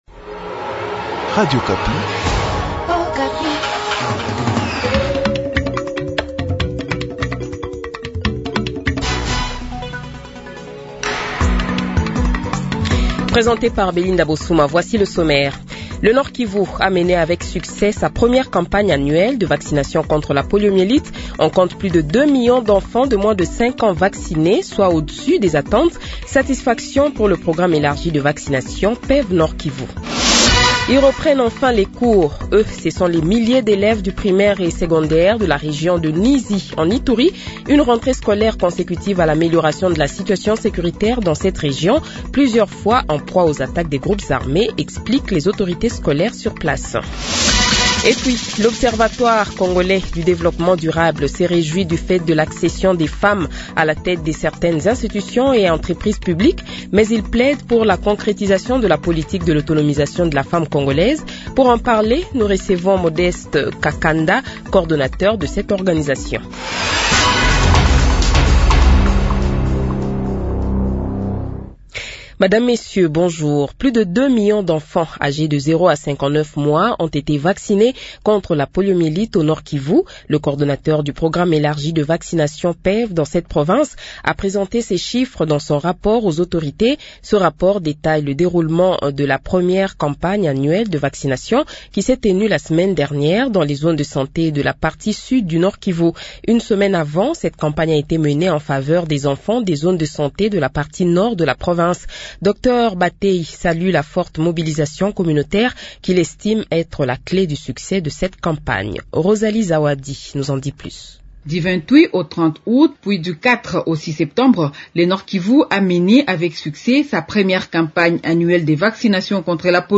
Le Journal de 12h, 14 Septembre 2025 :